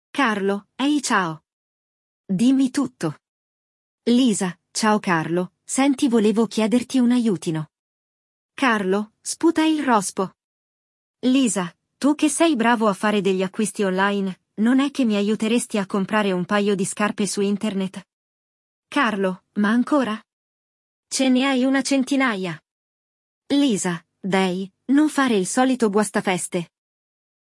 Neste episódio, vamos acompanhar o diálogo entre dois amigos muito próximos. Uma delas está louca para comprar sapatos novos, mas, como fazer compras online não é o seu forte, ela liga para seu amigo para pedir ajuda.
Il dialogo